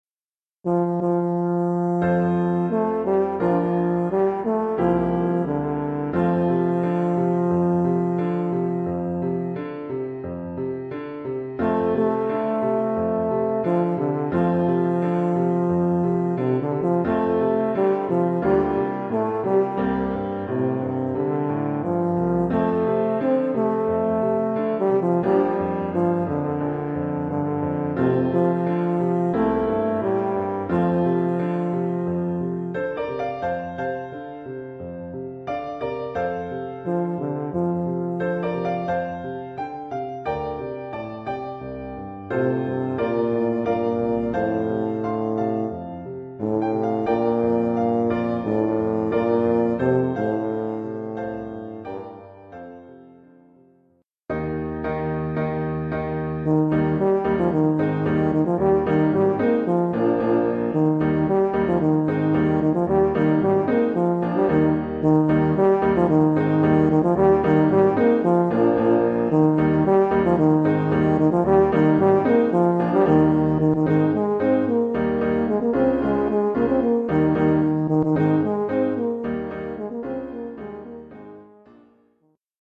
Oeuvre pour saxhorn basse /
euphonium / tuba et piano
Niveau : préparatoire (1er cycle).